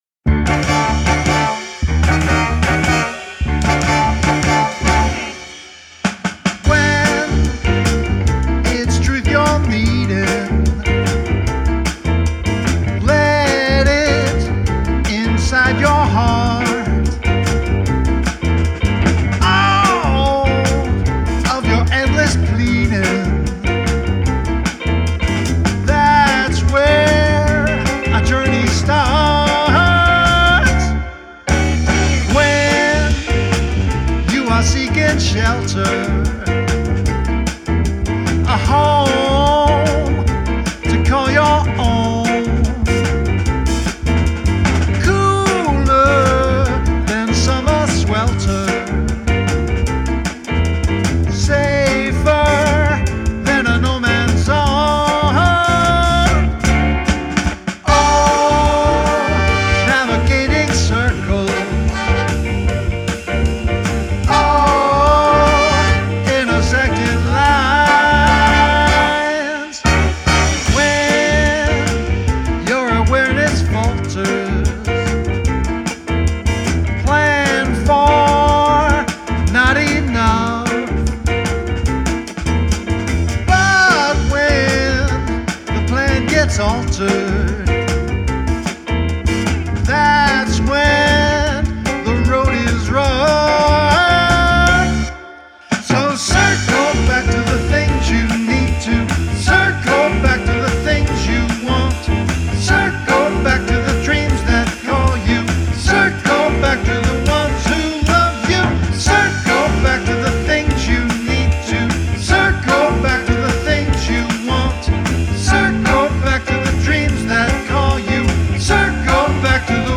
SINGER/MULTI-INSTRUMENTALIST & MUSIC PRODUCER
GRAMMY-recognized jazz musician
pianist, singer, trumpeter, and composer